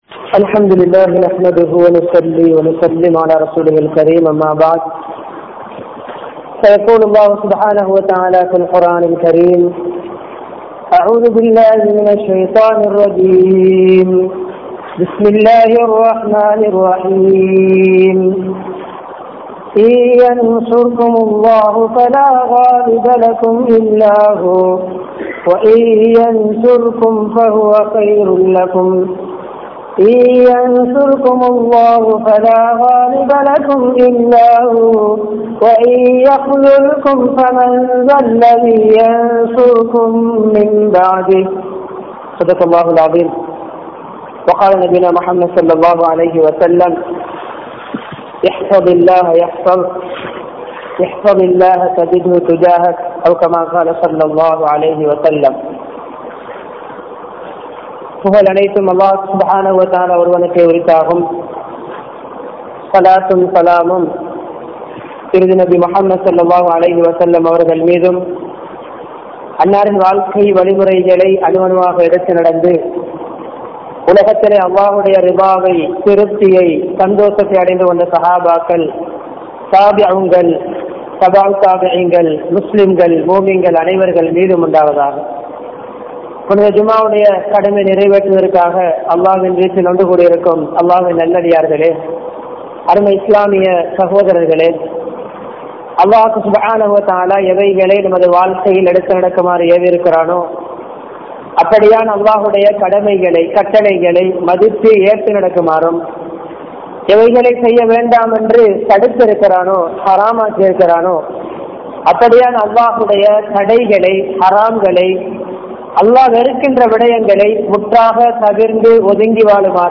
Duaa`s & Dhikkr for a Happy Life | Audio Bayans | All Ceylon Muslim Youth Community | Addalaichenai
Mutwal Jumua Masjidh